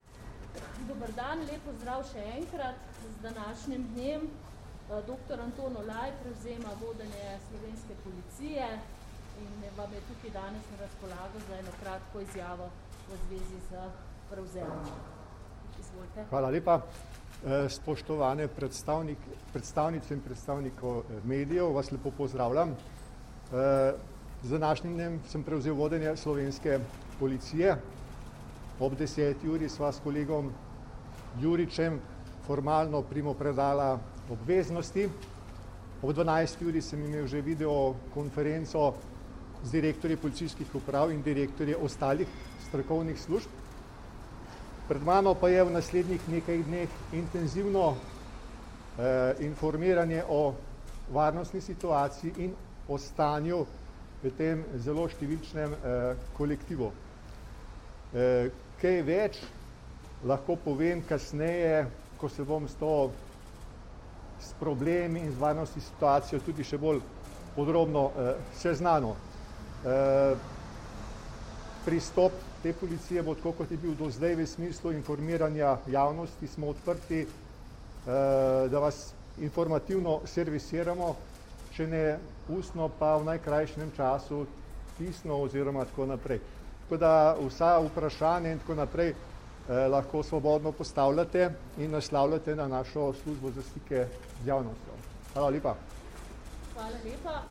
Zvočni posnetek izjave dr. Antona Olaja